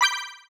menu-multiplayer-click.wav